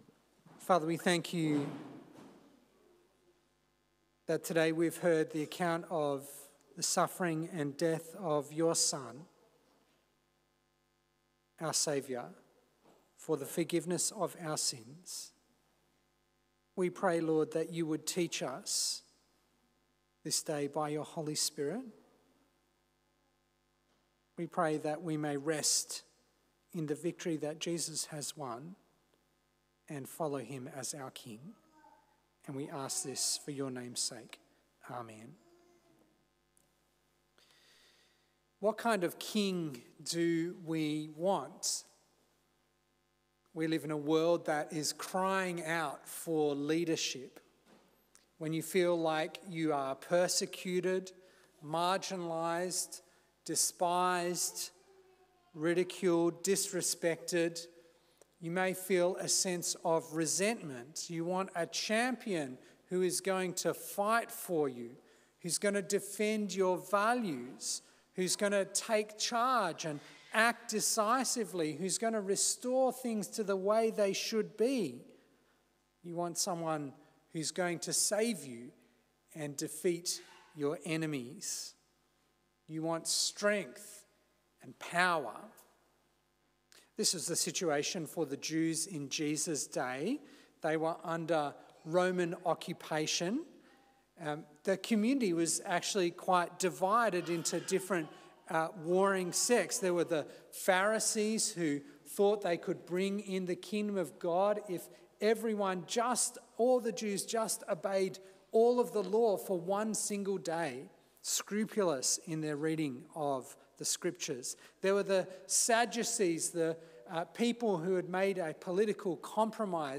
A Sermon on Matthew 21